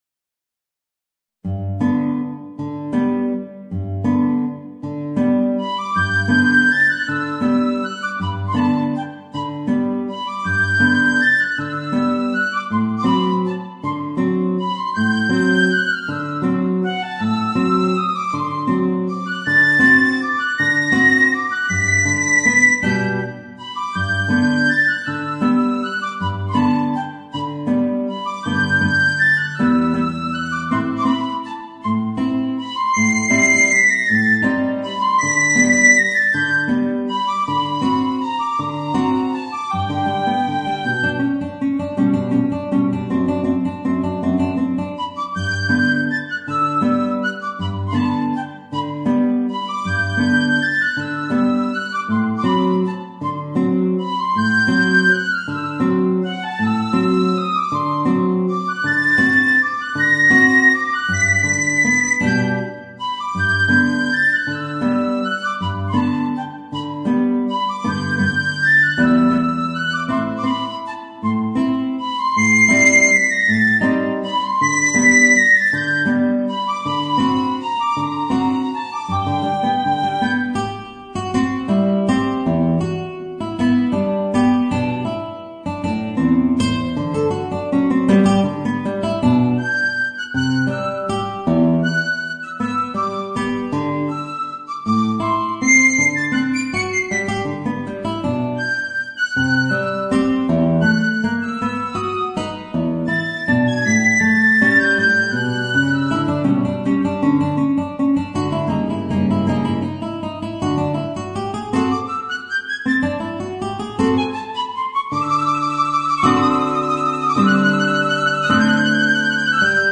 Voicing: Piccolo and Guitar